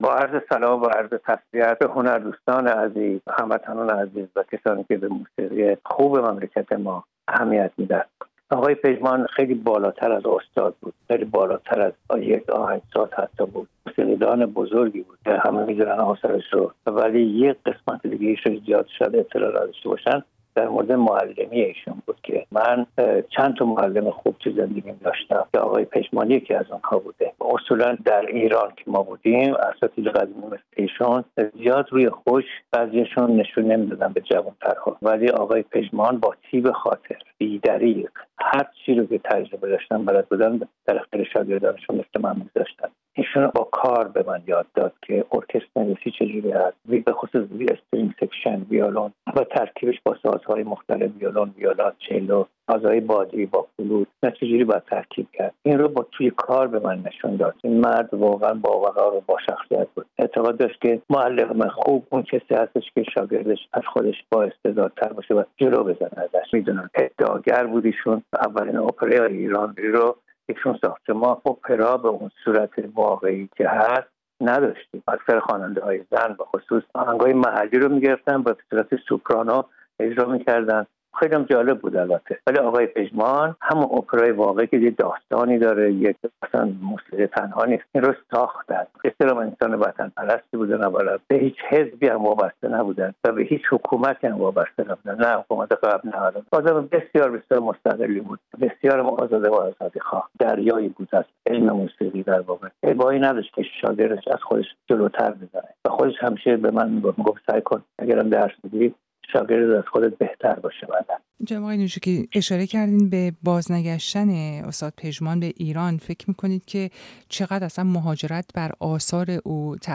احمد پژمان، آهنگساز و موسیقی‌دان سرشناس، هفتم شهریورماه در سن ۹۰ سالگی در شهر لس‌آنجلس آمریکا چشم از جهان فروبست. صادق نوجوکی، که دیگر آهنگساز صاحب‌نام ایرانی در سبک موسیقی پاپ ایران مقیم لس‌آنجلس آمریکا است، در گفت‌وگو با رادیوفردا از زوایای زندگی و شخصیت استاد احمد پژمان گفته است.